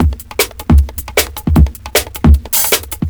JUNGLE1-R.wav